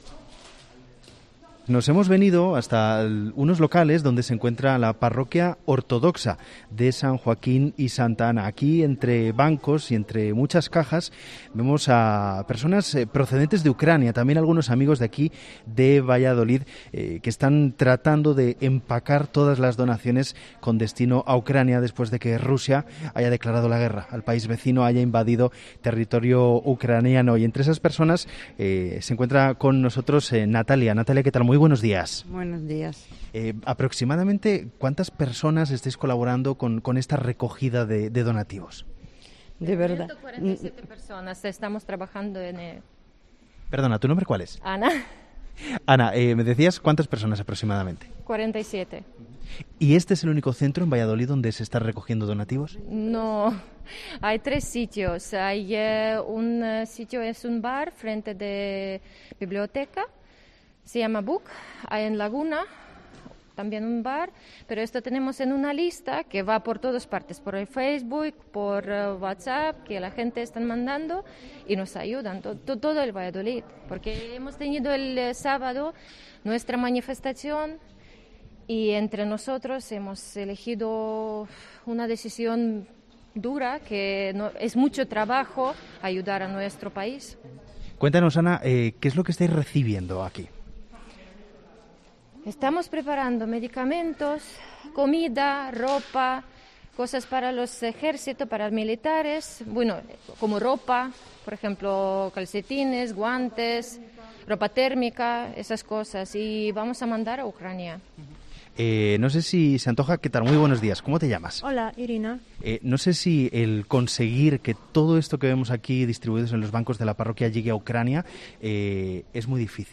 COPE Valladolid se traslada a una parroquia ortodoxa donde familias preparan un envío de material a Ucrania